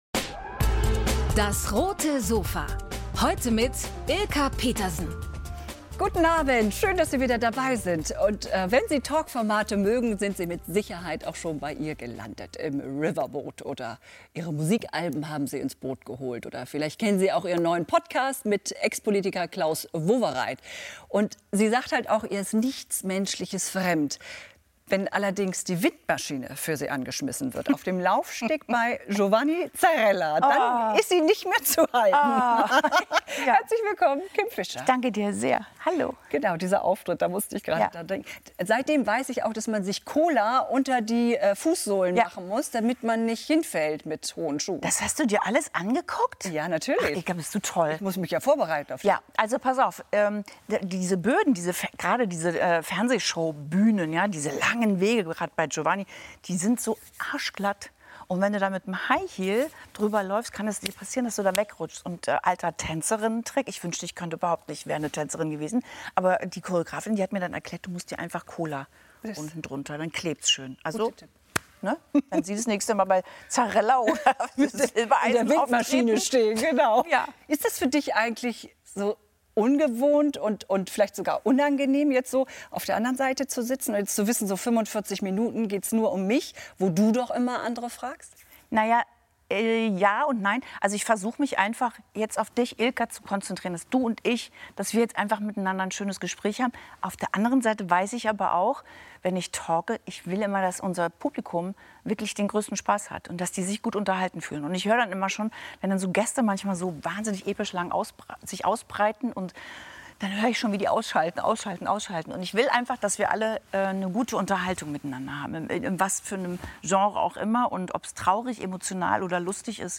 Auf dem Roten Sofa lassen wir uns von Fisher berichten, wie ihre Karriere Ende der 1980er-Jahre als Sängerin begann und wo sie und Klaus Wowereit sich über den Weg gelaufen sind.